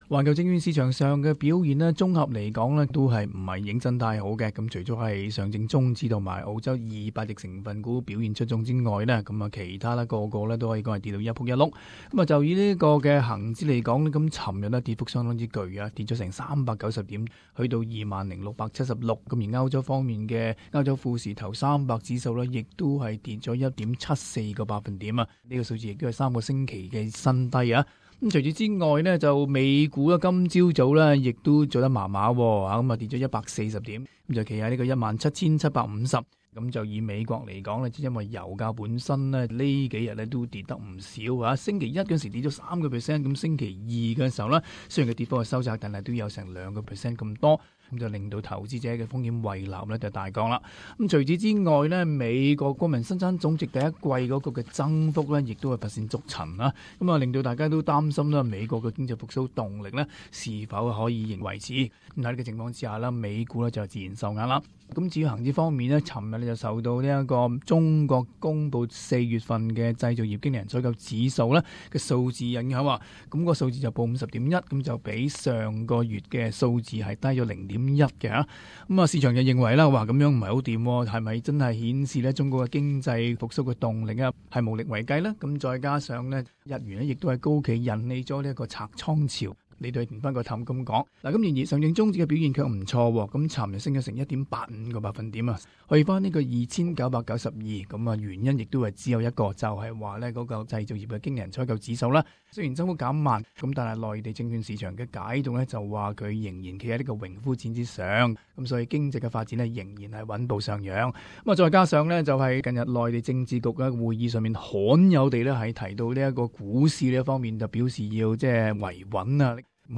Interview Morgan's Analyst